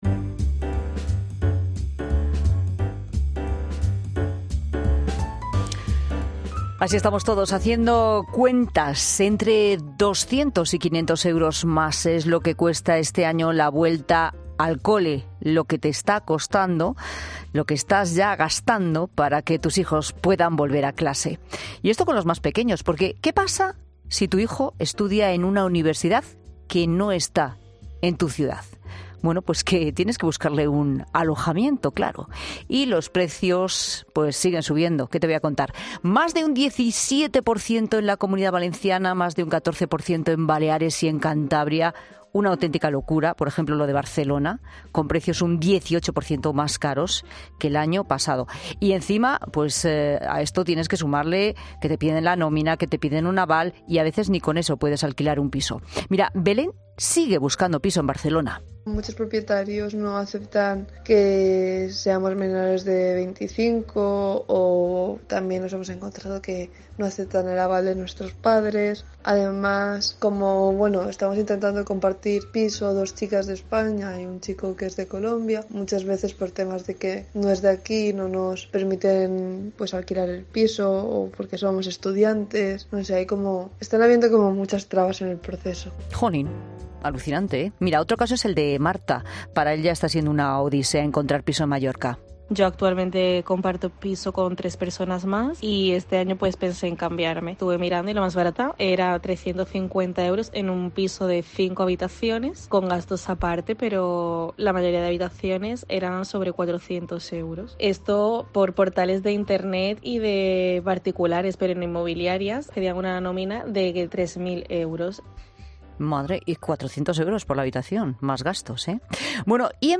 AUDIO: En La Tarde recibimos distintos testimonios para analizar cómo está el mercado del alquiler para los estudiantes.